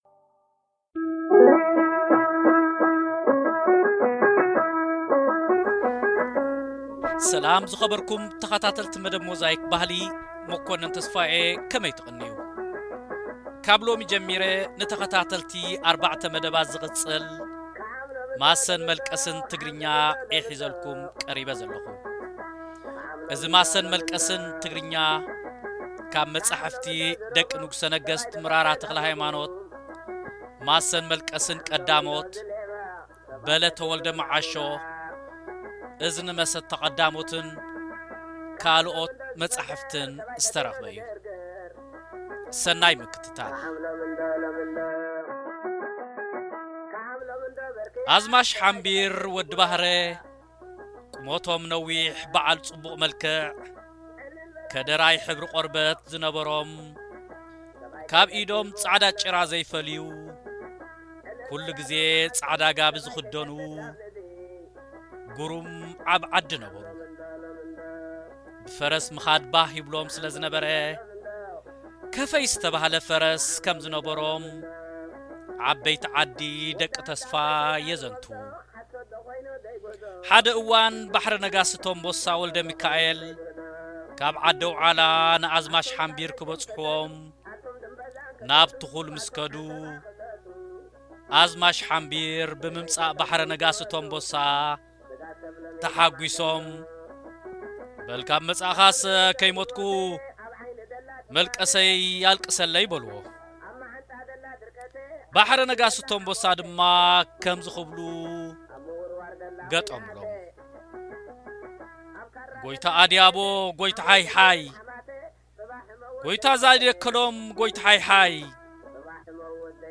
ንዝቕጽል ኣርባዕተ ክፋላት ዝቐርብ ዝተፈላለዩ ማሰኛታትን መላቐስትን ዝገበሩዎም ማሰን መልቀስን ትግርኛ ቀዳማይ ክፋሉ እንሆ ሎሚ ይቐርብ